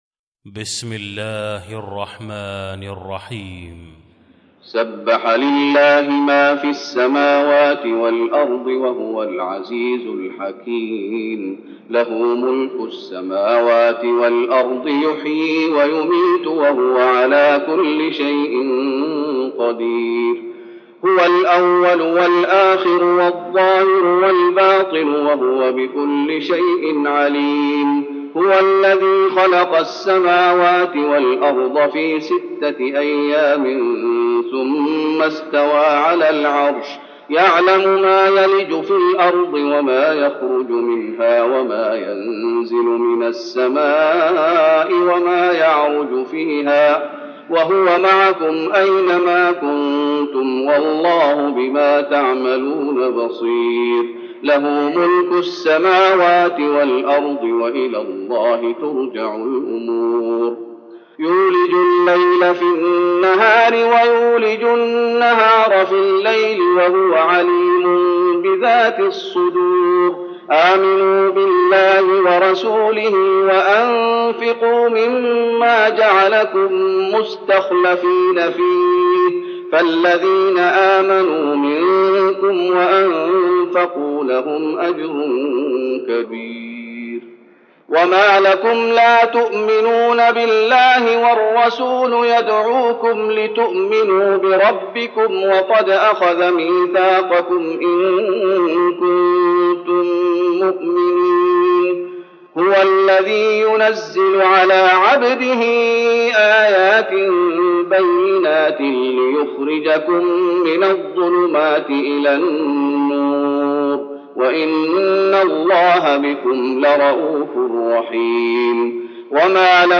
المكان: المسجد النبوي الحديد